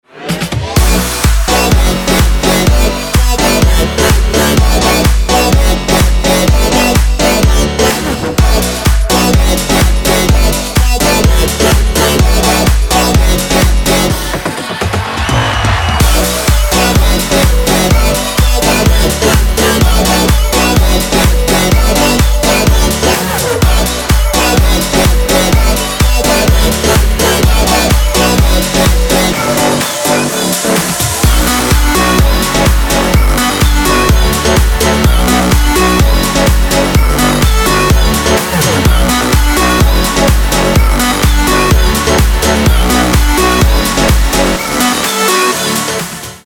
dance
future house
club